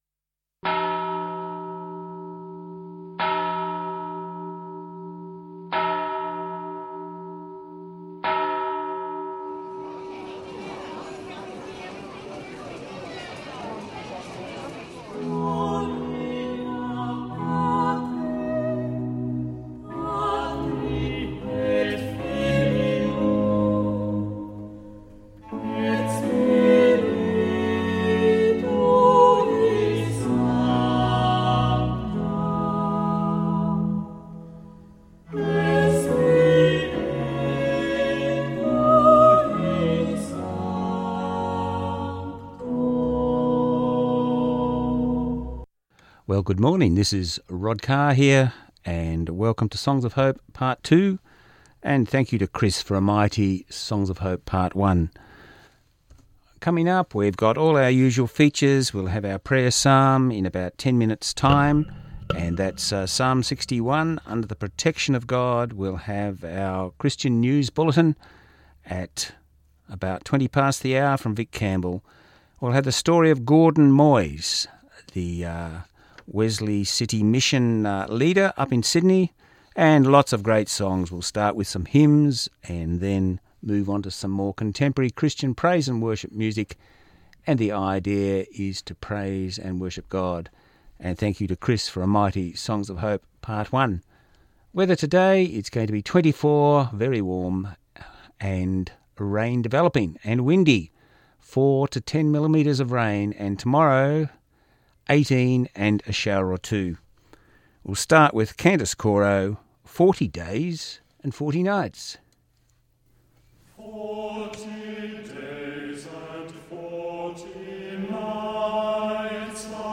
16Oct16 1hr Christian music
Listen again to a one hour recording of Songs of Hope part 2. Broadcast and recorded on Sunday 16Oct16 on Southern FM 88.3